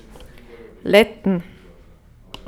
Lettn / Begriff-ABC / Mundart / Tiroler AT / Home - Tiroler Versicherung